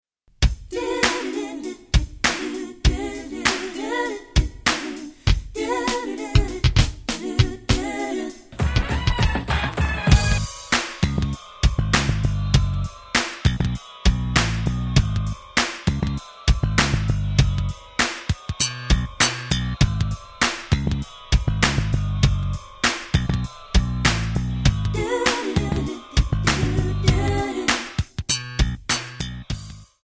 C/D
MPEG 1 Layer 3 (Stereo)
Backing track Karaoke
Pop, 1990s